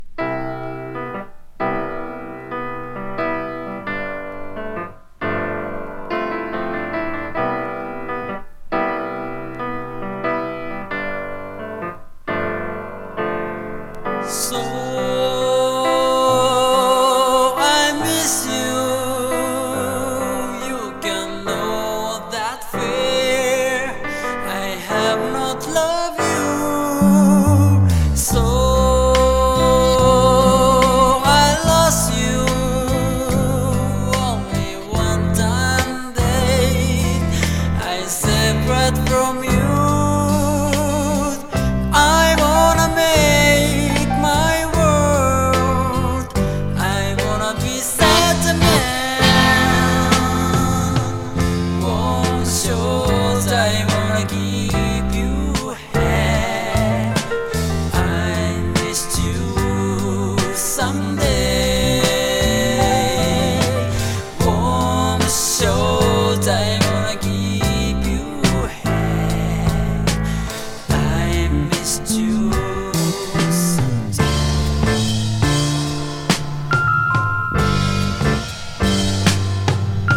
SSW風〜バラードの